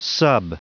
Prononciation du mot sub en anglais (fichier audio)
Prononciation du mot : sub